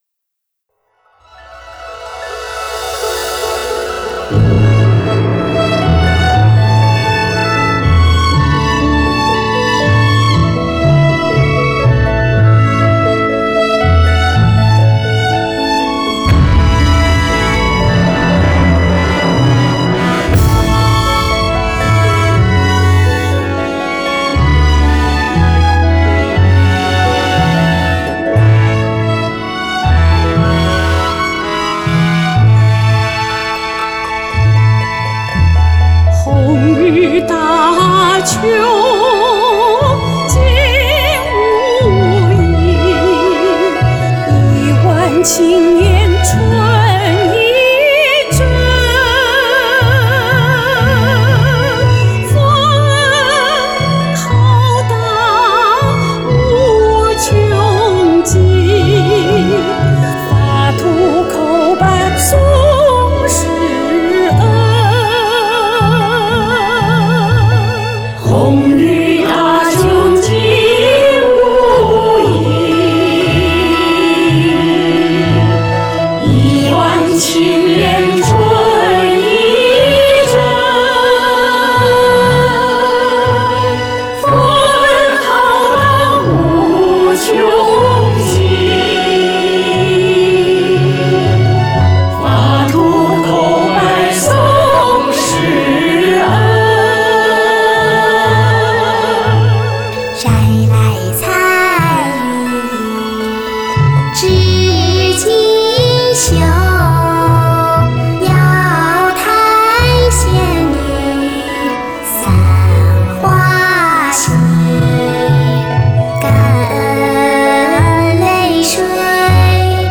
【庆祝513】合唱：颂师恩 | 法轮大法正见网